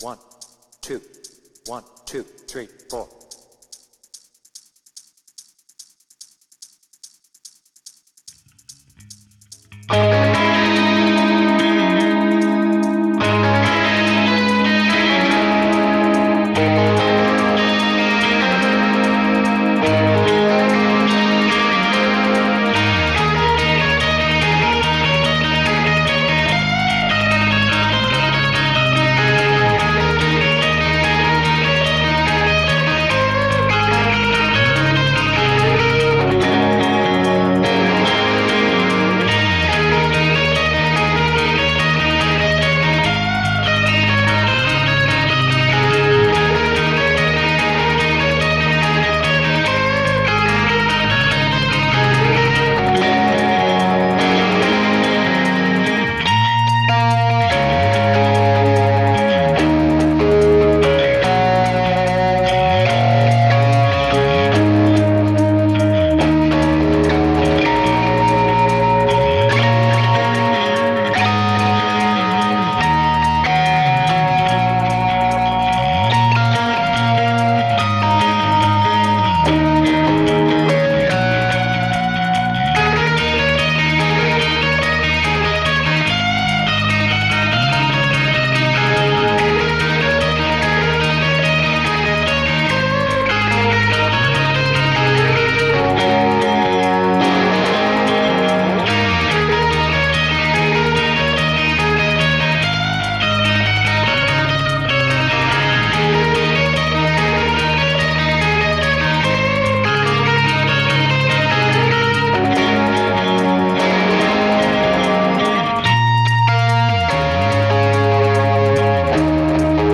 BPM : 145
Tuning : Eb
Without vocals
Based on the studio and Dortmund live version